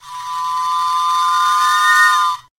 かすれた笛は、楽器の中でも特徴的な音色を持つ一つです。